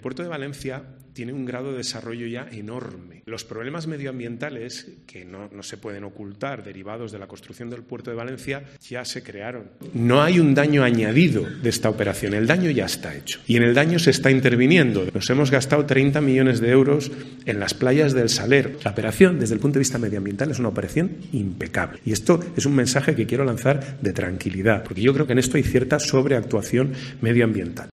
Puente, en un desayuno organizado por Nueva Economía Fórum este jueves, ha afirmado que "los problemas medioambientales, que no se pueden ocultar", derivados de la construcción de dicha infraestructura "ya se crearon" y ha añadido que no los va a originar esta nueva operación.